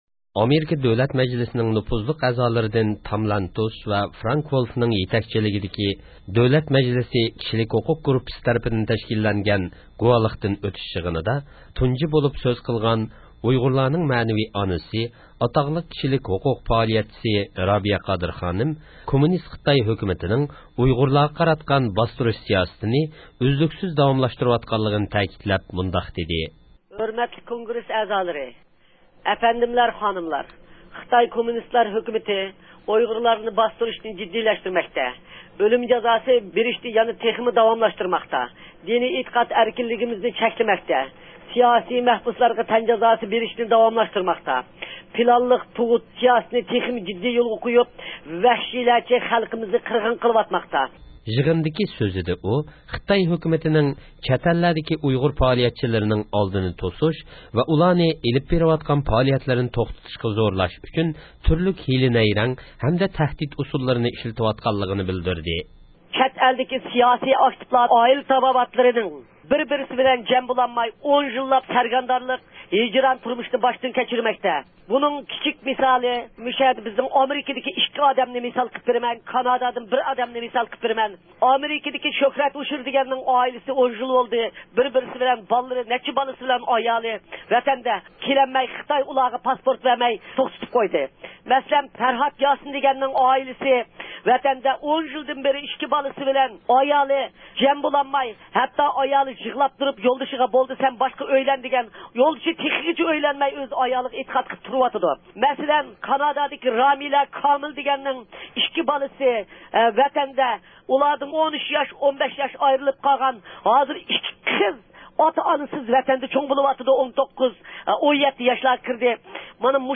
رابىيە قادىر خانىم يىغىندا قىلغان سۆزىدە، خىتاي ھۆكۈمىتىنىڭ ئۇيغۇر خەلقىغە قاراتقان باستۇرۇش سىياسىتىنى داۋاملاشتۇرىۋاتقانلىقىنى تەكىتلەپ، ئامېرىكا ھۆكۈمىتى ۋە دۆلەت مەجلىسىدىن، ئۇيغۇرلارنىڭ كىشىلىك ھوقۇقىغا ھۆرمەت كۆرسىتىشى ئۈچۈن، بېيجىڭ ھۆكۈمىتىگە بېسىم ئىشلىتىشىنى تەلەپ قىلدى.